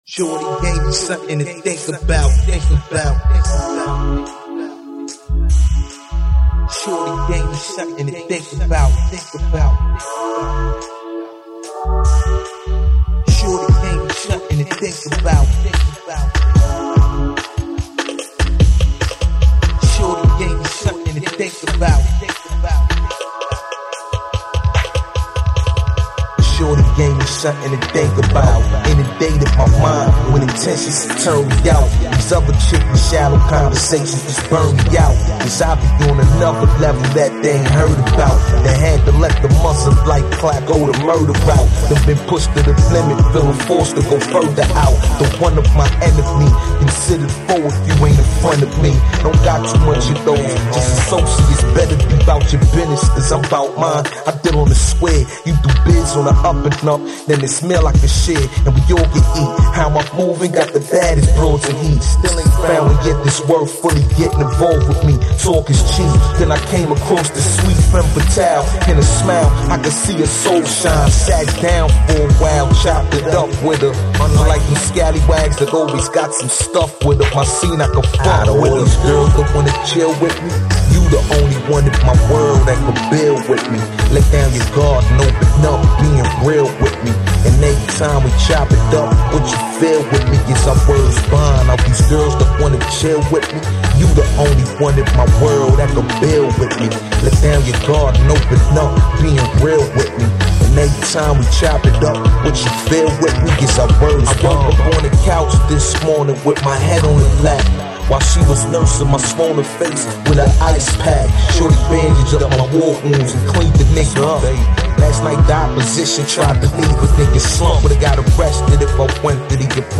Recorded in Prison